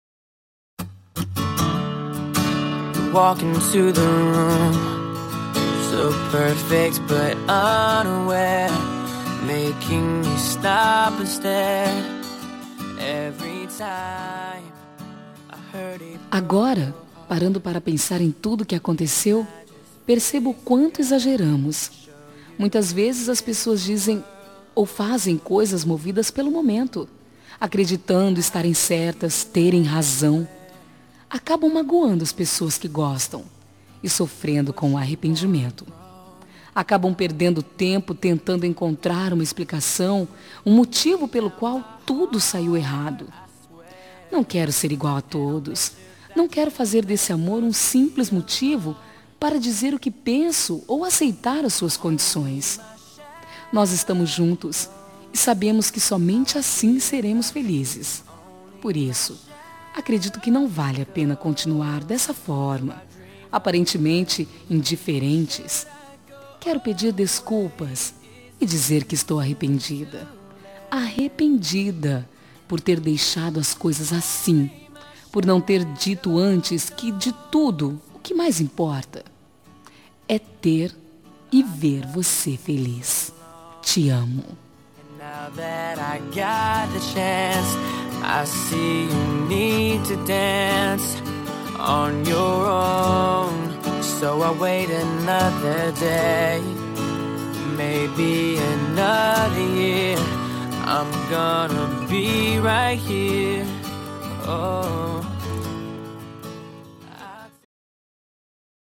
Telemensagem de Desculpas – Voz Feminina – Cód: 343